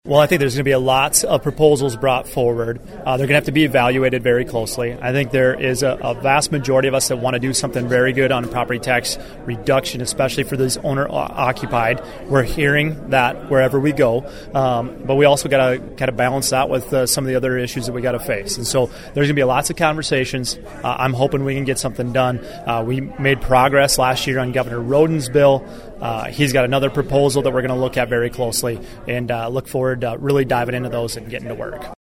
District 8 Senator Casey Crabtree of Madison says he anticipates competing ideas.
Crabtree appeared at a meeting of “Republican Friends” in Yankton.